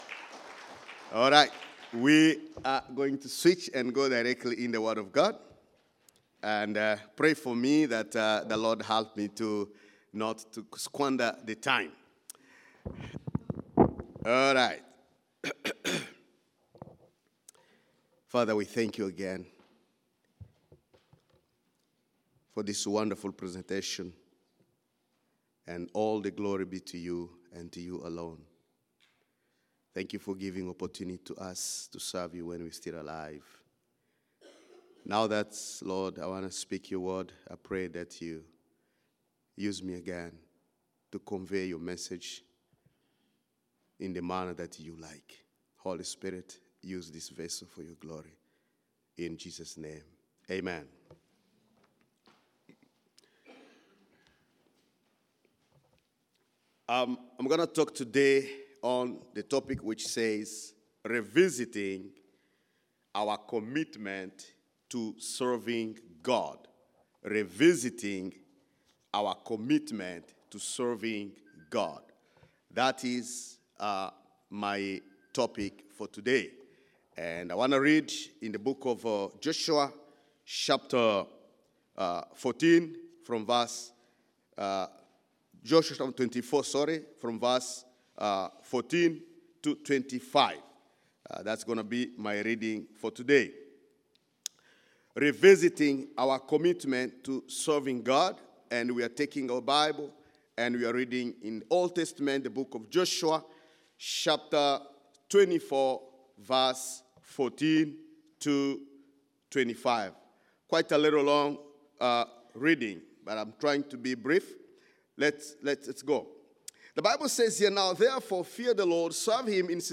Sermons | Mountainview Christian Fellowship